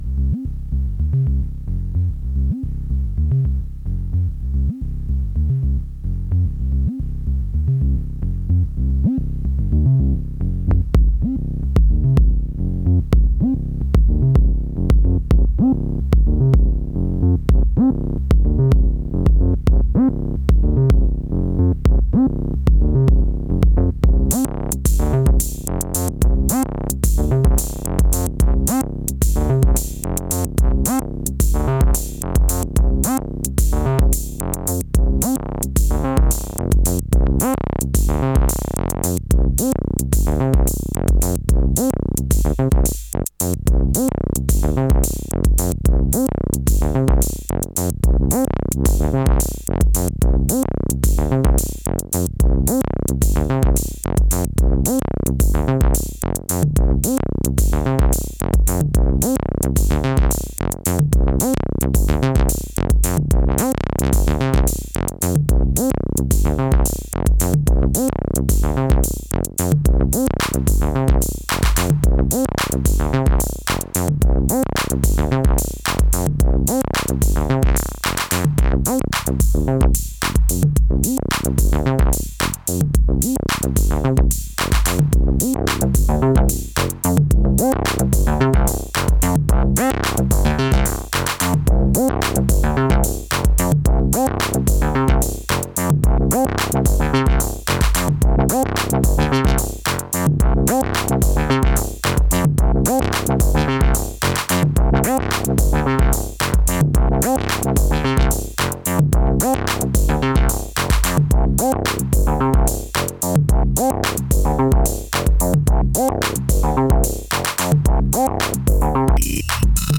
Edit: I felt bad so I recorded a separate take in Live as well so you can properly hear it.